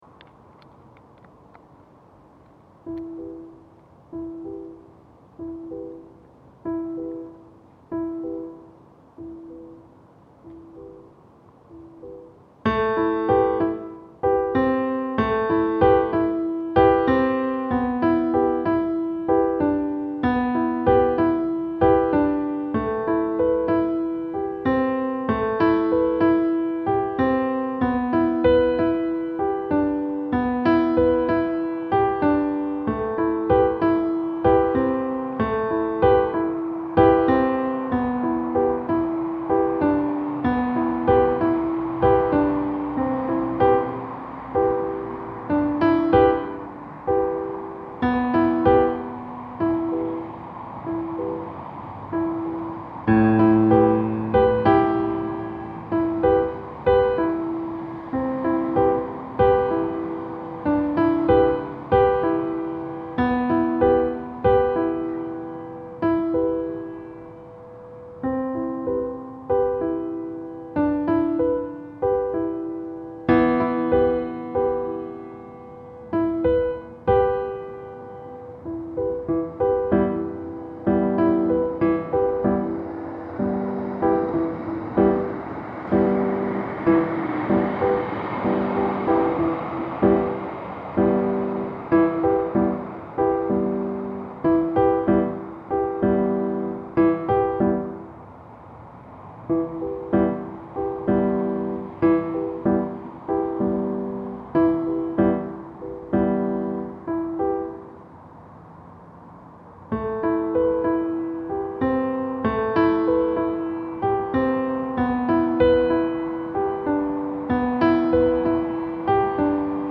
15 tracks, fifty minutes of soothing sounds.